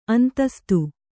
♪ antastu